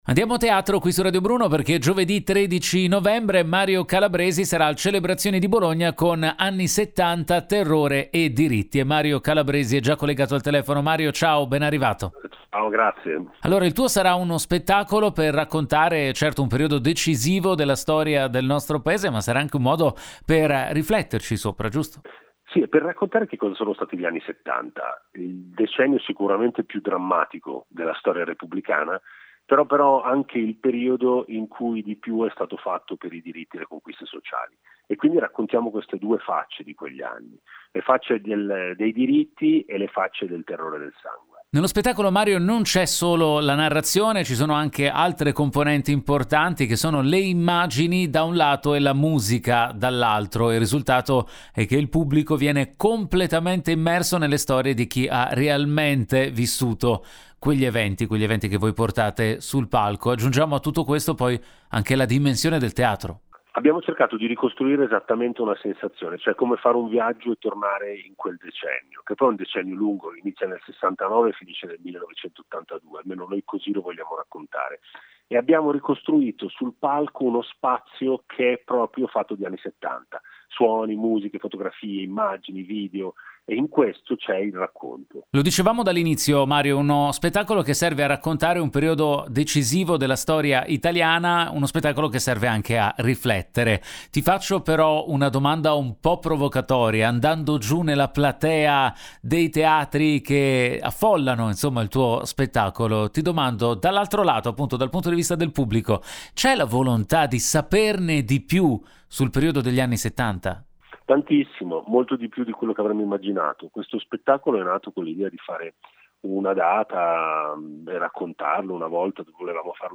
Mario Calabresi, intervistato al telefono